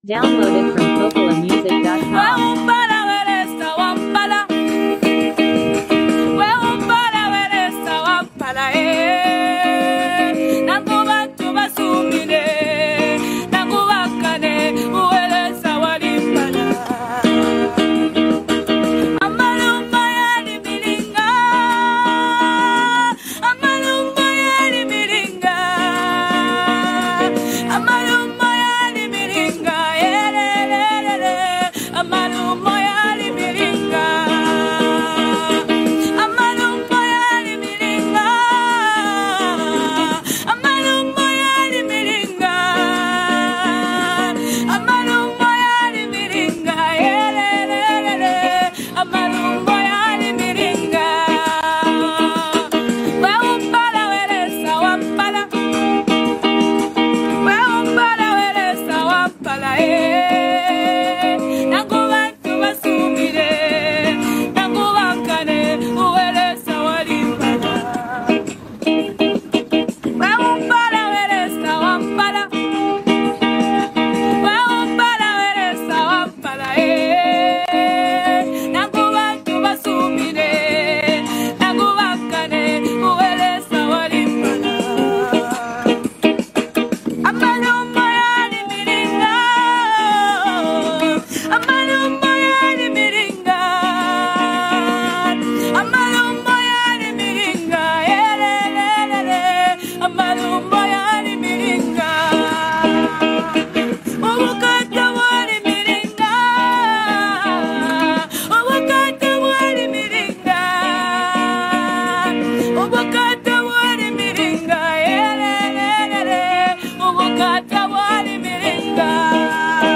Through heartfelt lyrics and a soulful melody